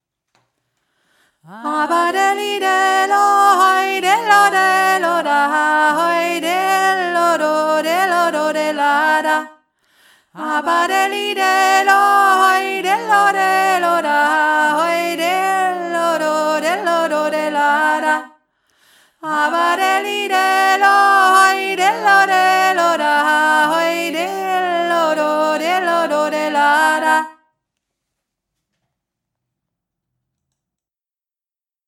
georgisches Arbeitslied
Dreistimmig
Hohe Stimme
aba-deli-delo-hohe-stimme.mp3